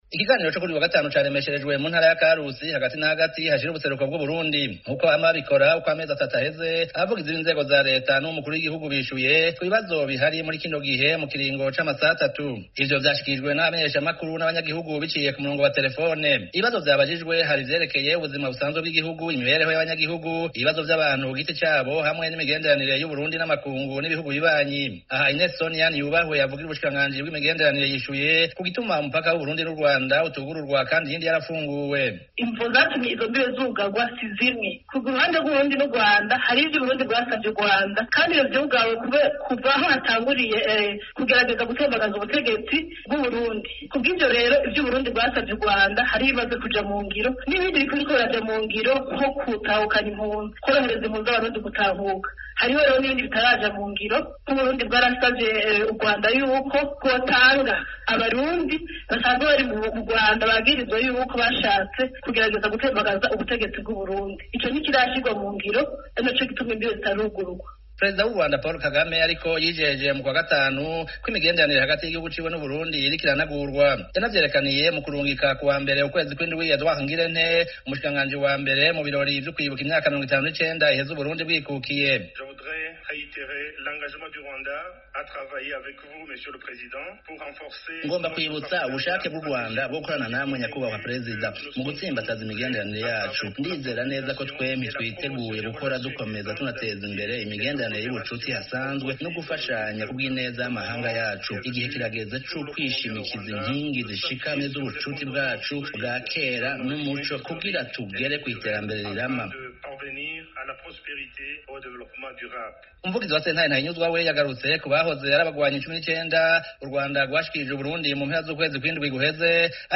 Havuzwe kandi ibindi bibazo birimwo ukurungika mu myitozo ya gisirikare urwaruka ruhejeje ay'isumbuye imbere y'uko ruja muri kaminuza. Ikiganiro co kuri uyu wa gatanu caremesherejwe mu ntara ya Karusi, hagati na hagati hashira ubuseruko bw’Uburundi.
Nk’uko bama babikora uko amezi atatu aheze, abavugizi b’inzego za reta n’uw’umukuru w’igihugu bishuye ku bibazo bihari muri kino gihe mu kiringo c’amasaha atatu.